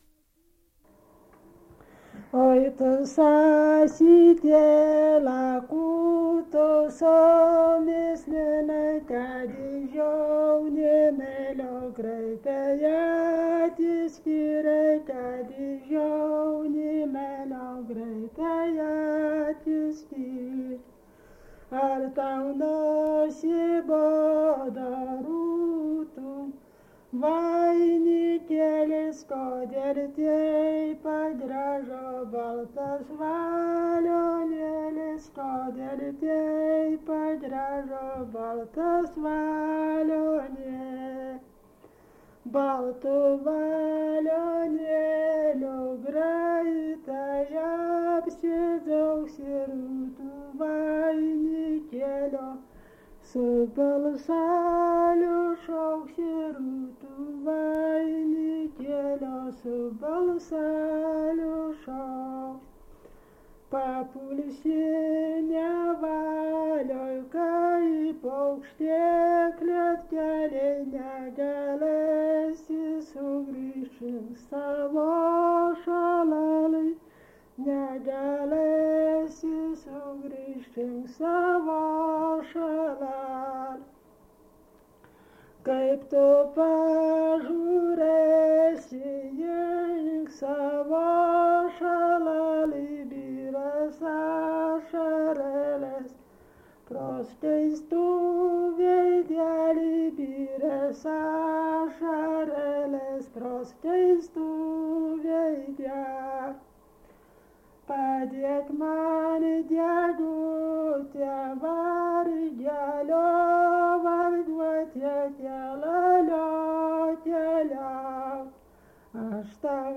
daina
Neravai (Varėna)